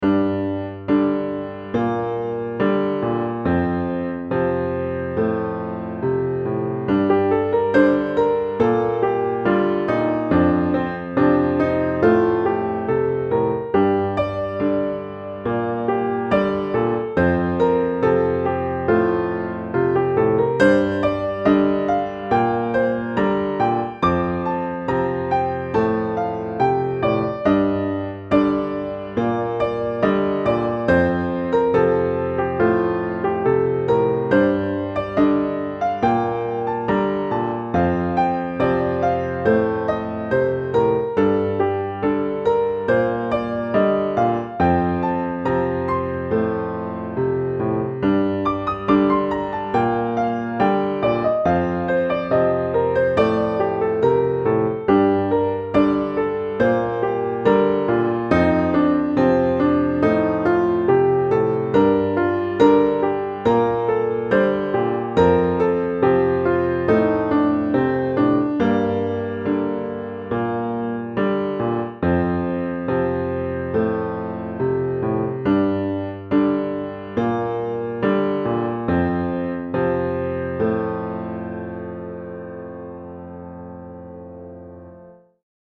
Solo de piano minimalista
piano
melodía
minimalista
solo